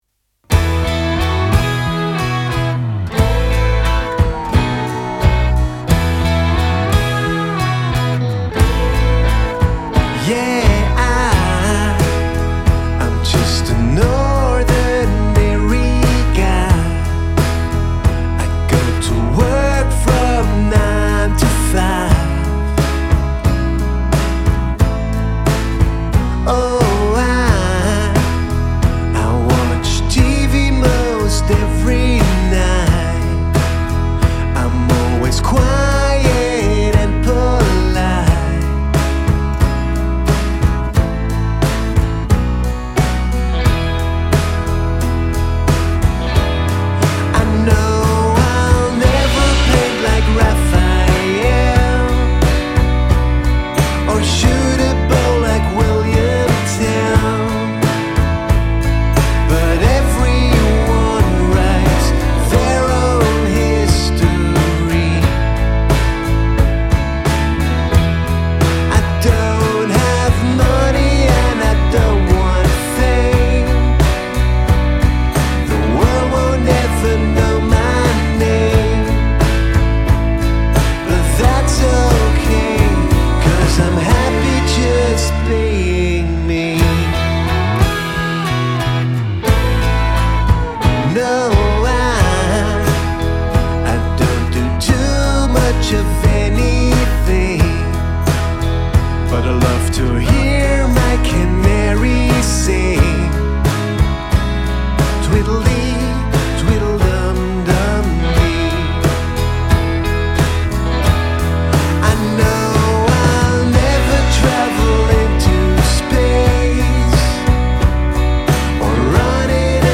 Guitars, Pedal Steel Guitar, Mandolin,
Backing Vocals, Shaker&Tambourine
Lead Vocals
Bass Guitar
Drums
Keyboards